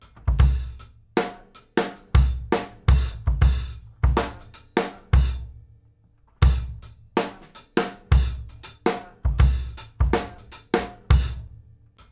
GROOVE 7 06L.wav